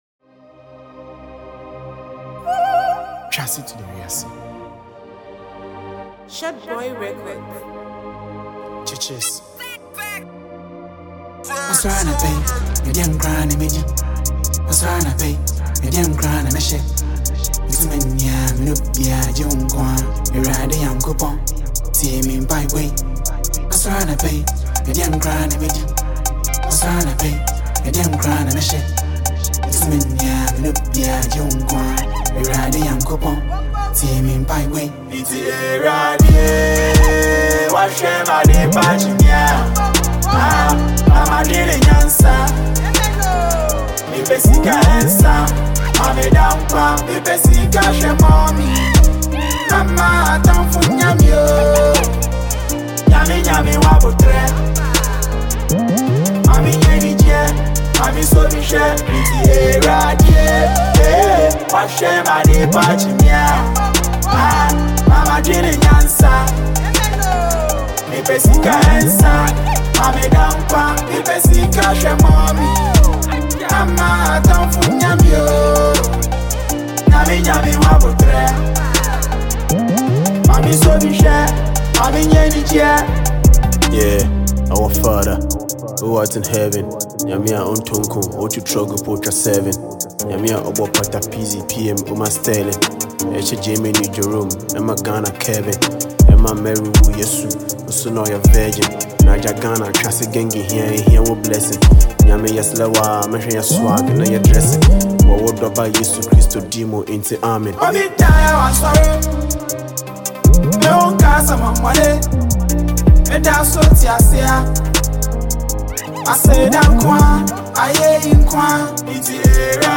Ghanaian rapper sensation